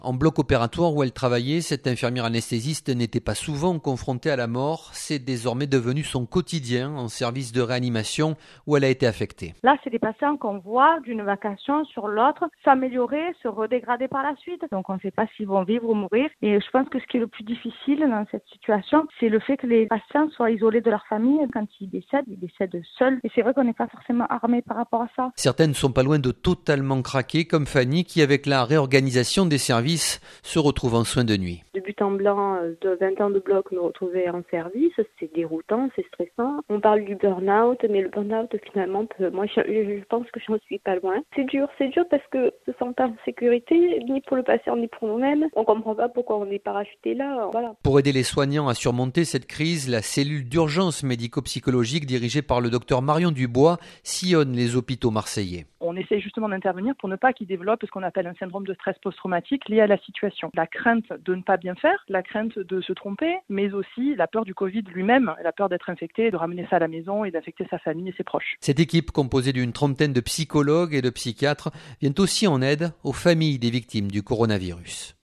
Un reportage
La voix embuée de larmes